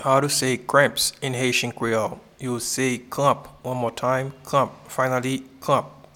Pronunciation and Transcript:
Cramps-in-Haitian-Creole-kranp.mp3